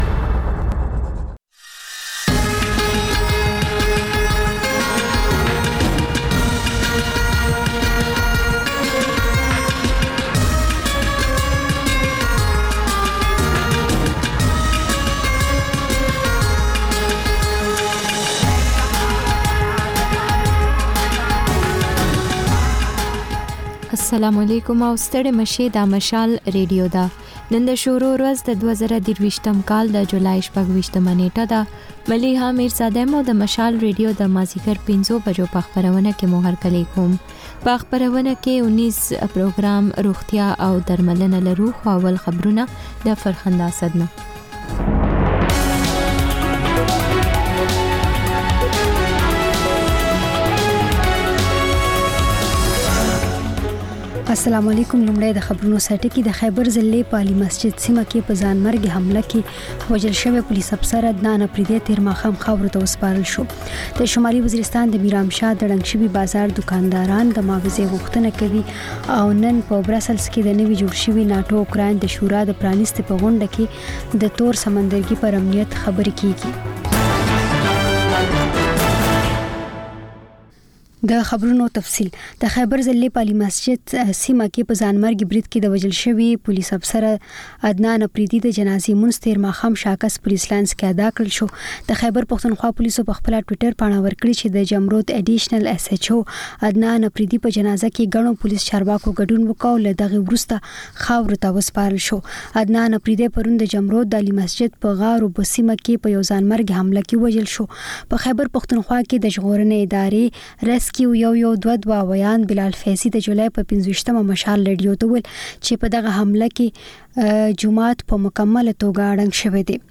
د مشال راډیو ماښامنۍ خپرونه. د خپرونې پیل له خبرونو کېږي، بیا ورپسې رپورټونه خپرېږي. ورسره یوه اوونیزه خپرونه درخپروو. ځېنې ورځې دا ماښامنۍ خپرونه مو یوې ژوندۍ اوونیزې خپرونې ته ځانګړې کړې وي چې تر خبرونو سمدستي وروسته خپرېږي.